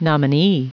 Prononciation du mot nominee en anglais (fichier audio)
Prononciation du mot : nominee